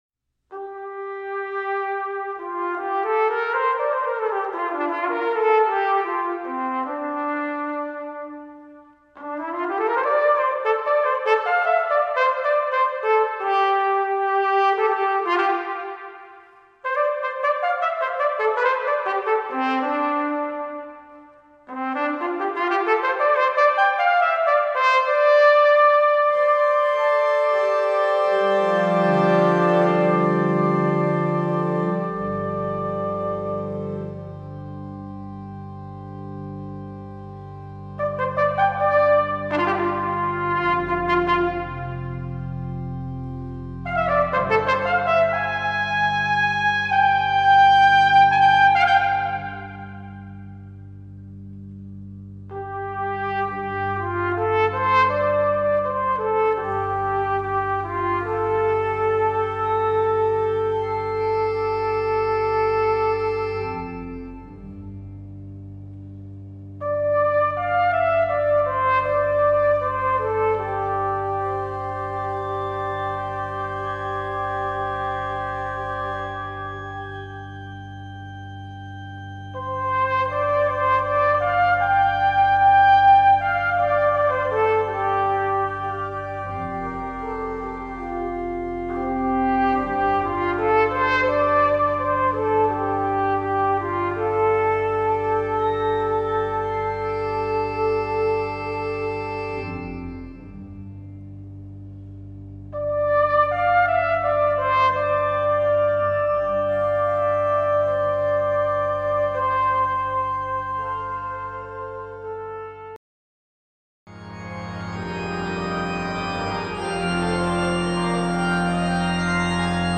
Trumpet and Organ
Written for flugelhorn/piccolo trumpet and organ